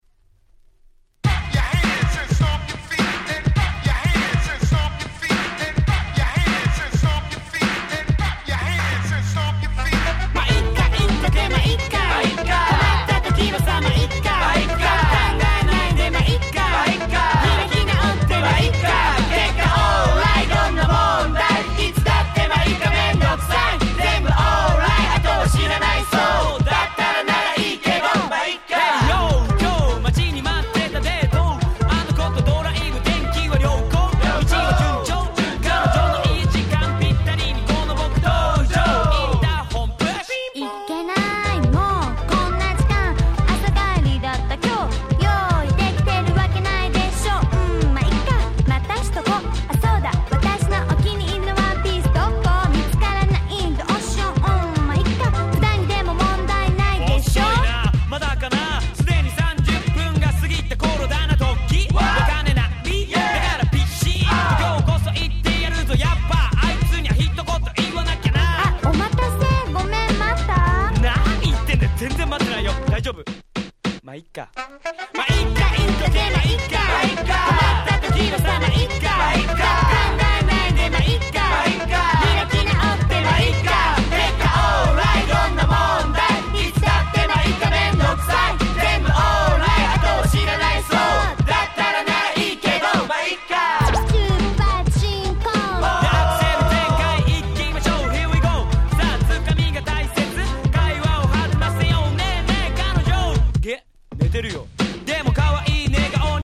95' J-Rap Classic !!